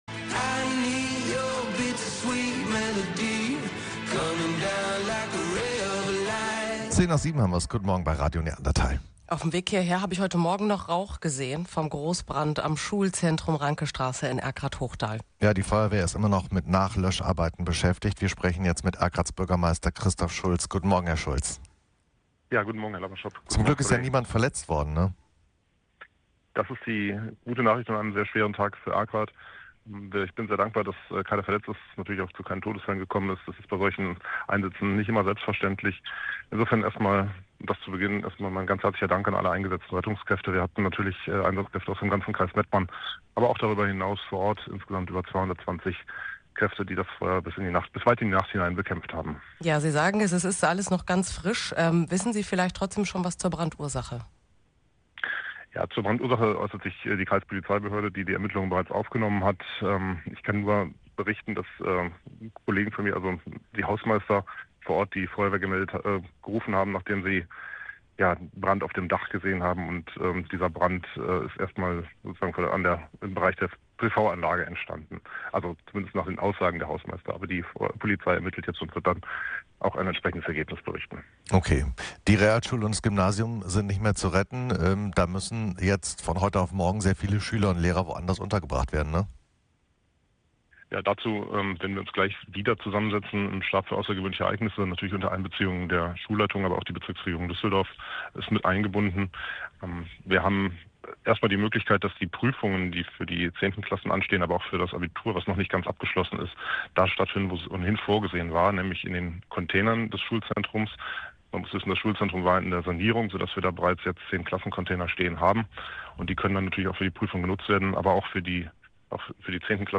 Das Schulzentrum im Ortsteil Hochdahl wurde dabei fast vollständig zerstört. Wir haben uns mit dem Erkrather Bürgermeister Christoph Schulz über die dramatischen Auswirkungen des Feuers unterhalten.